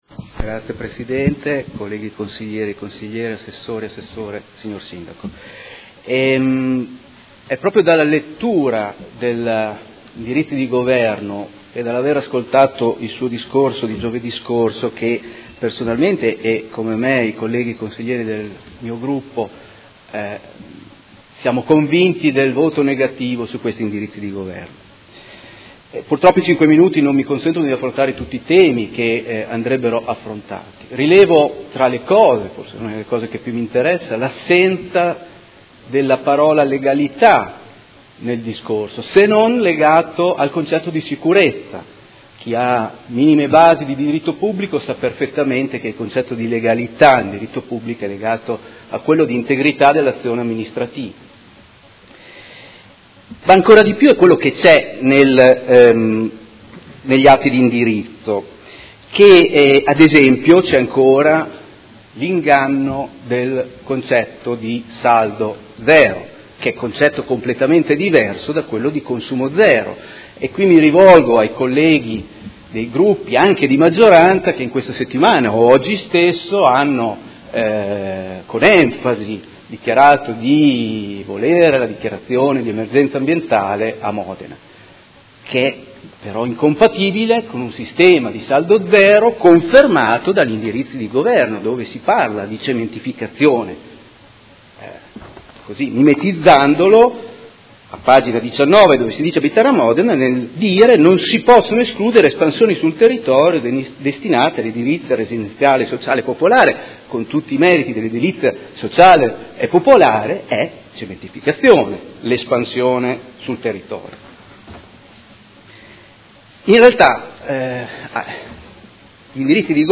Giovanni Silingardi — Sito Audio Consiglio Comunale
Seduta del 20/06/2019. Dibattito su proposta di deliberazione: Indirizzi Generali di Governo 2019-2024 - Discussione e votazione